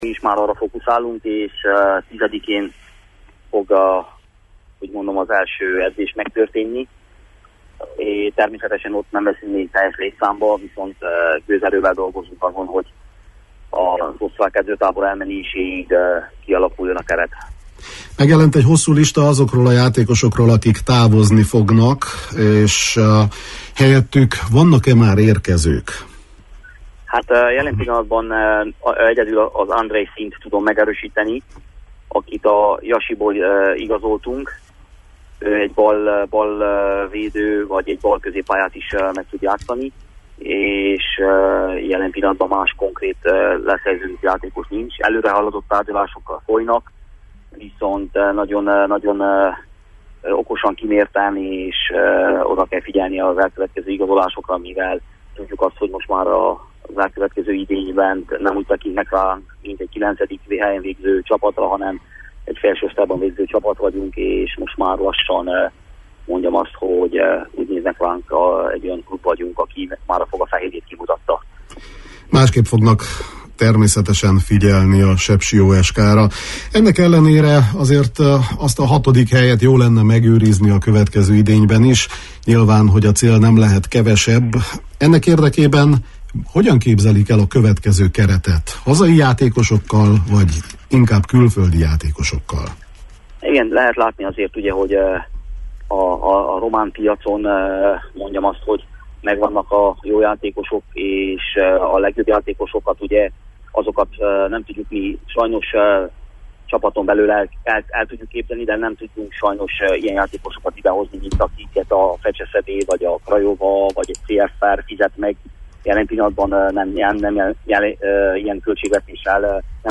a KISPAD című sportműsorunkban beszélgettünk a felmerülő kérdésekről: